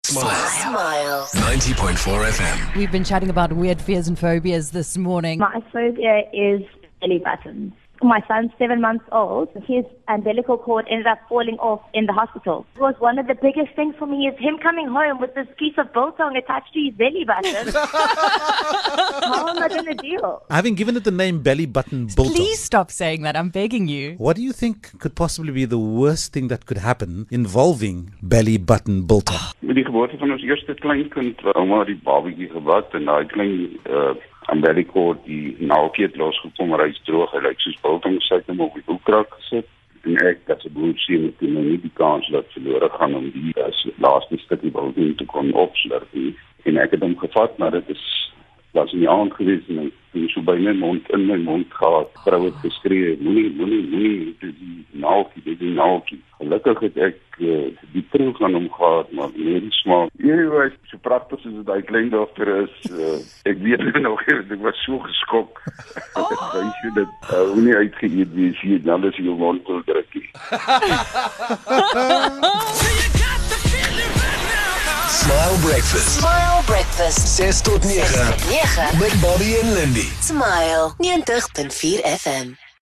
We have no idea how it happened but a conversation about peoples' unusual phobias led to a call from someone who had a slight confession. He accidentally ate something not fit for human consumption.